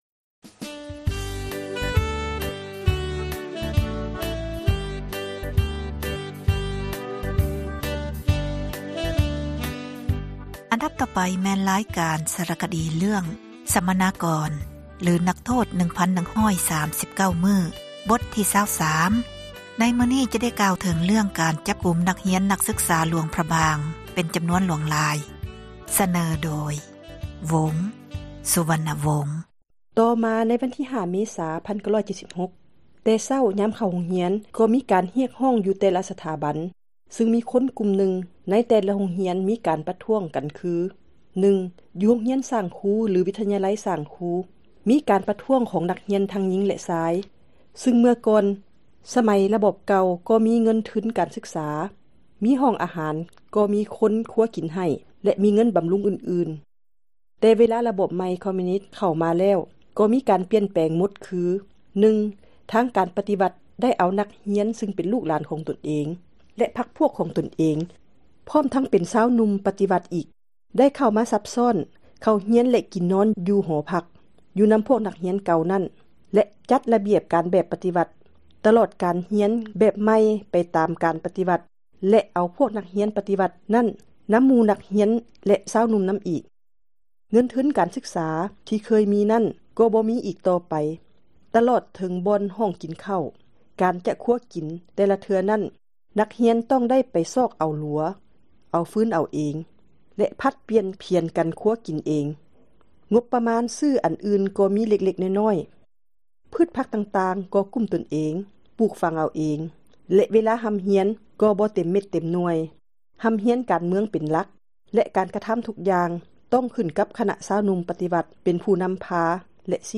ສາຣະຄະດີ ເຣື້ອງ ສັມມະນາກອນ ຫຼື ນັກໂທດ 1139 ມື້ ບົດ ທີ 23 ໃນມື້ນີ້ ຈະກ່າວເຖິງ ການຈັບ ນັກຮຽນ ນັກສຶກສາ ຢູ່ ແຂວງ ຫຼວງພຣະບາງ ຈໍານວນ ຫຼວງຫຼາຍ.